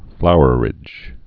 (flouər-ĭj)